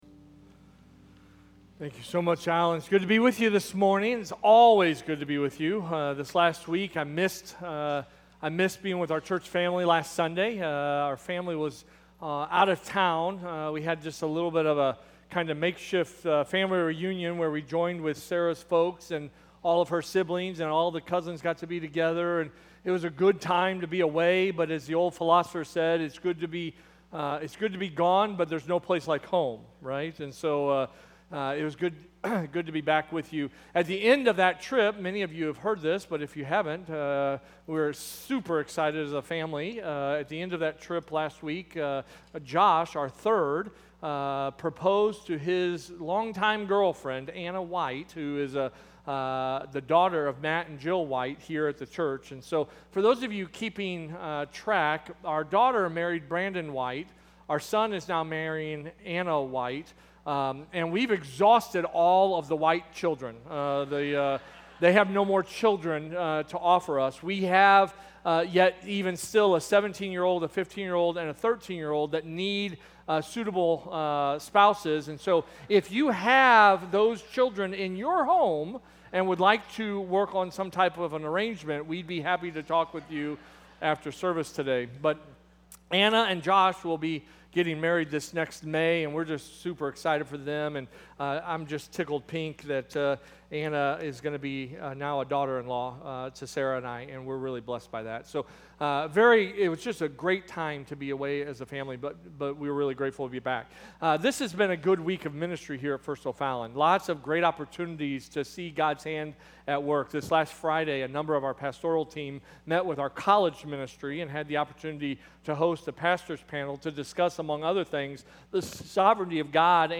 Sermons - First Baptist Church O'Fallon